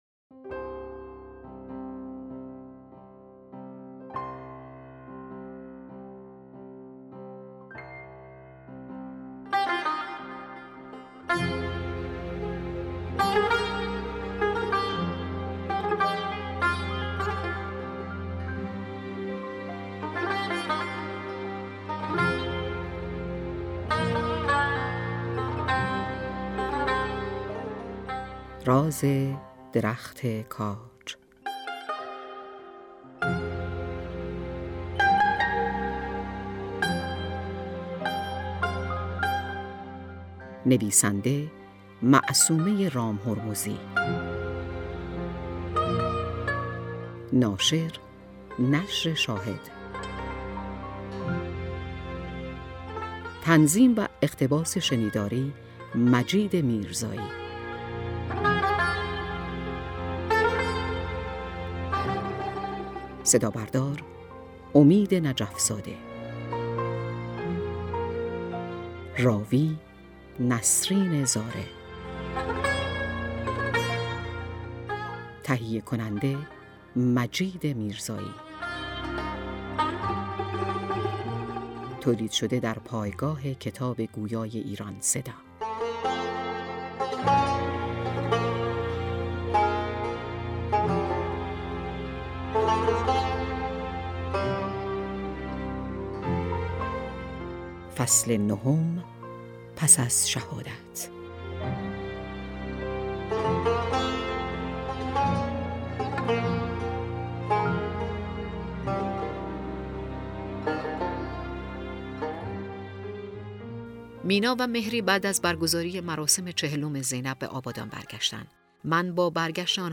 کتاب صوتی «راز درخت کاج»/ دانلود